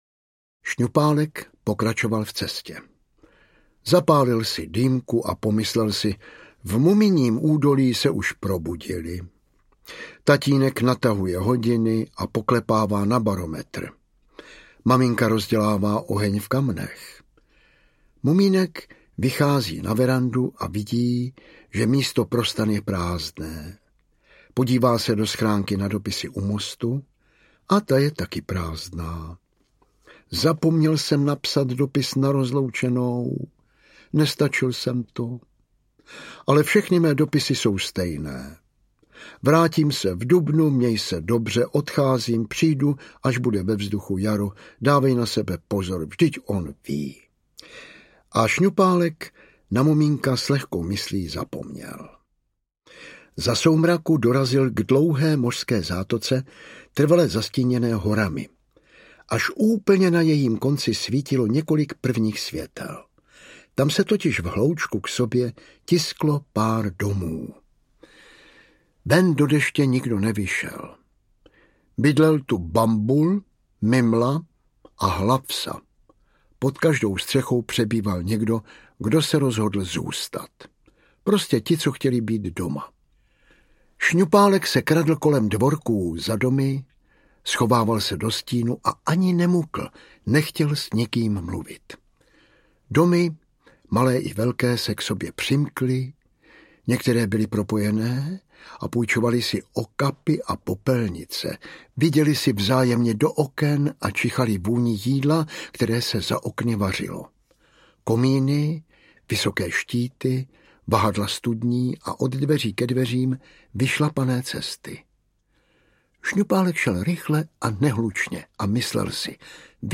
Pozdě v listopadu audiokniha
Ukázka z knihy
Vyrobilo studio Soundguru.
• InterpretJan Vlasák